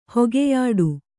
♪ hogeyāḍu